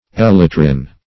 elytrin - definition of elytrin - synonyms, pronunciation, spelling from Free Dictionary Search Result for " elytrin" : The Collaborative International Dictionary of English v.0.48: Elytrin \El"y*trin\, n. [From Elytrum .]